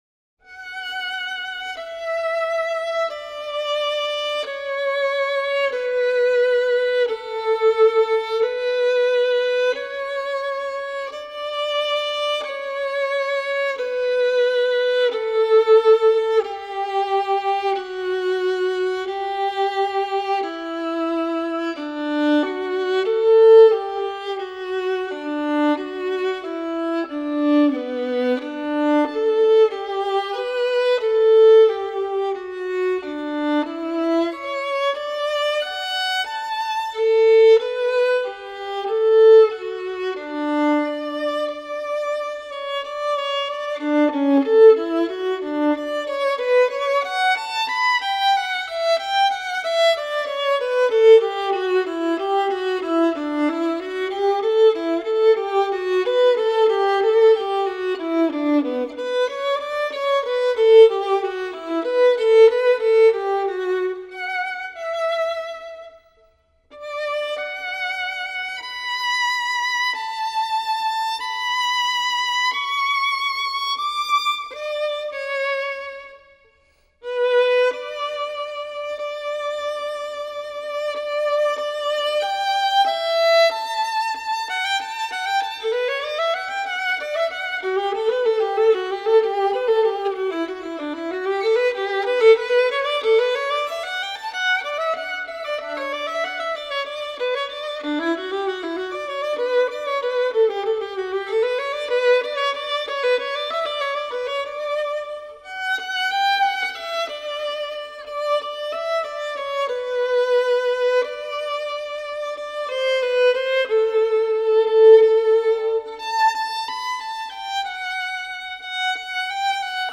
0307-卡农(帕海贝尔)独奏.mp3